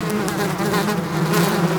MULTI INS04R.wav